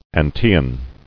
[An·tae·an]